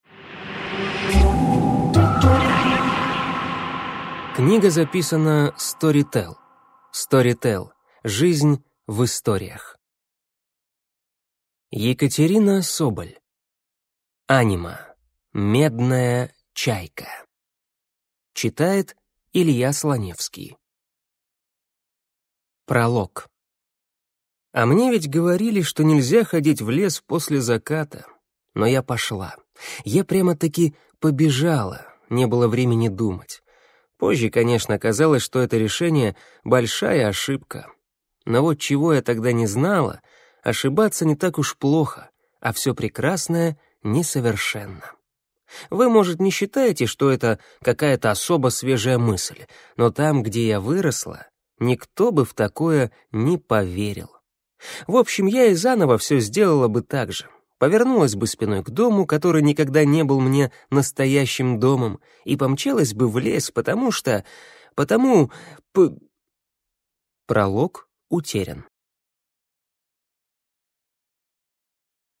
Аудиокнига Медная чайка | Библиотека аудиокниг
Прослушать и бесплатно скачать фрагмент аудиокниги